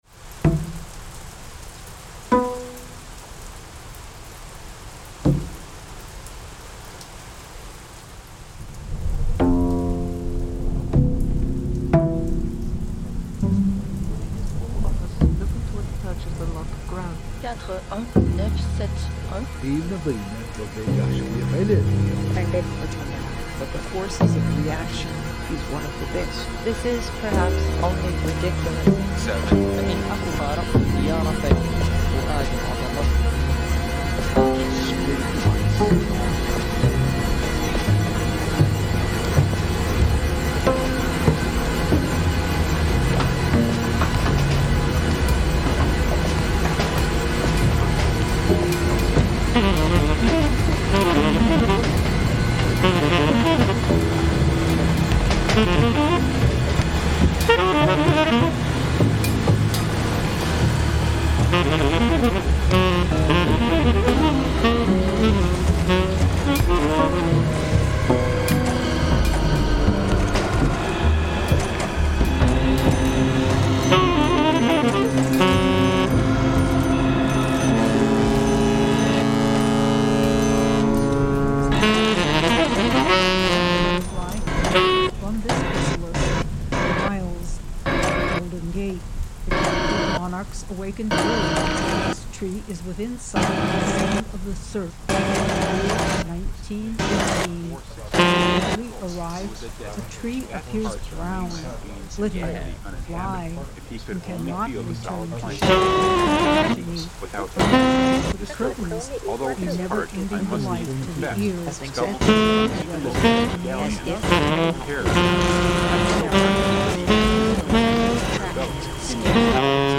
live streamed
Live Music, Saxophone
Additional (live mashed) sonic fictions